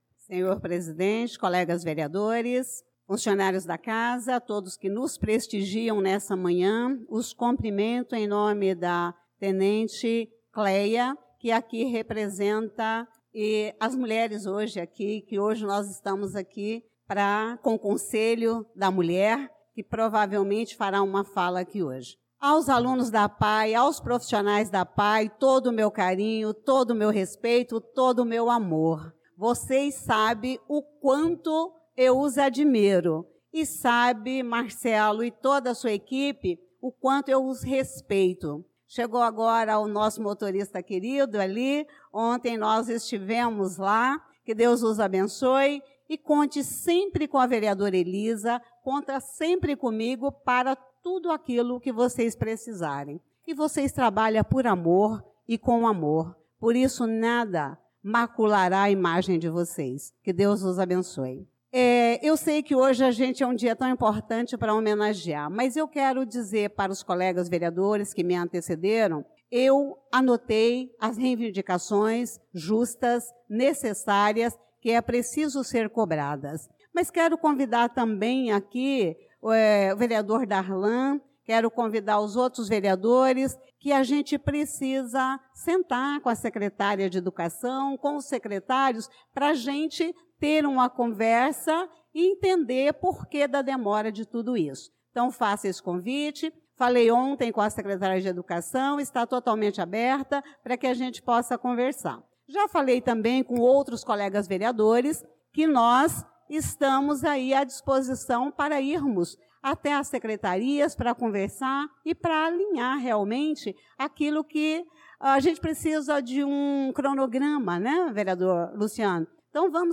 Pronunciamento da vereadora Elisa Gomes na Sessão Ordinária do dia 06/03/2025